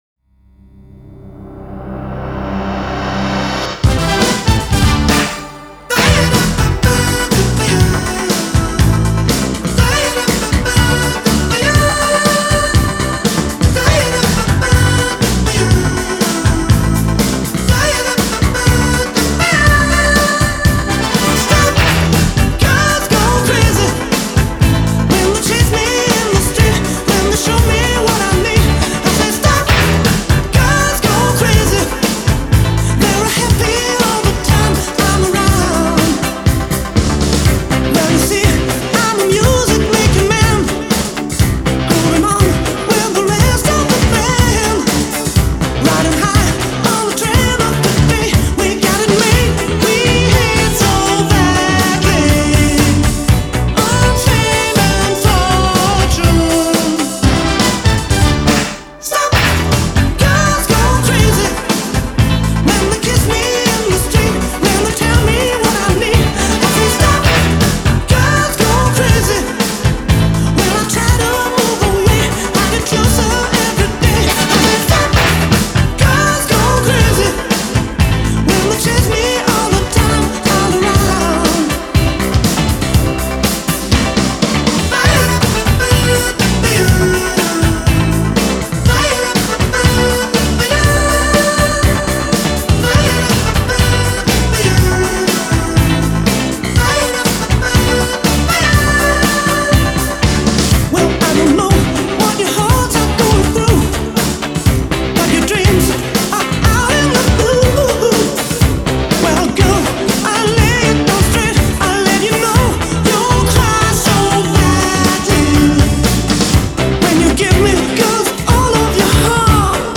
Жанр: Pop/Synth-pop